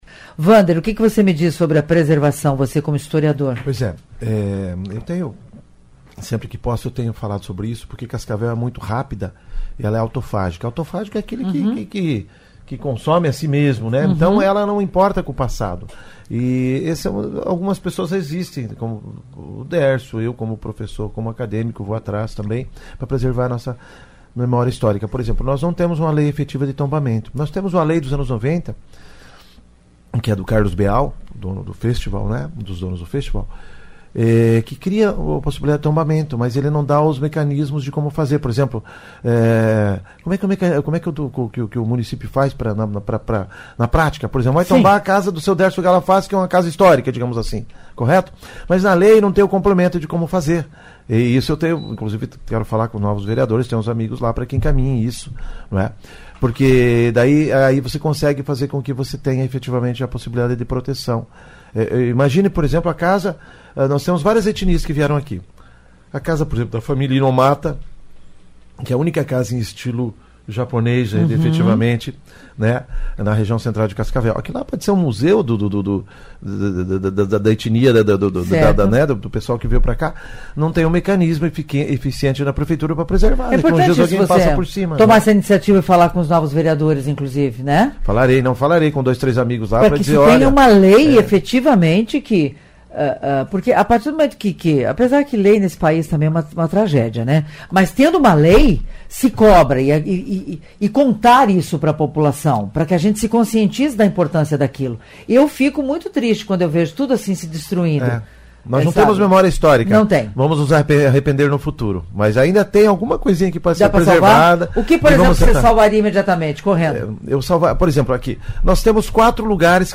Em entrevista ao Revista CBN, apresentado por Olga Bongiovanni